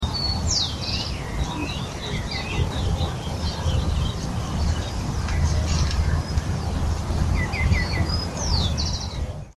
Caneleiro-preto (Pachyramphus polychopterus)
Nome em Inglês: White-winged Becard
Localidade ou área protegida: Reserva Ecológica Costanera Sur (RECS)
Condição: Selvagem
Certeza: Gravado Vocal